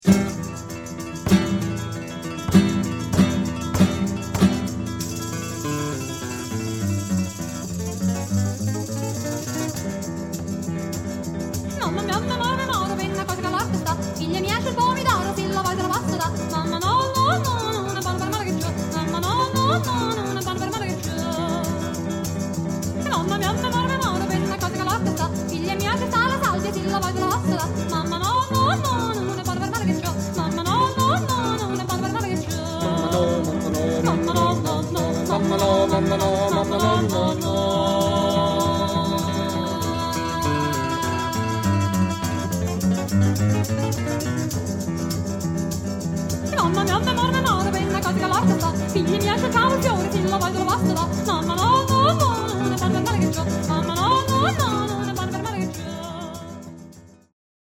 Chitarra e canzoni popolari del sud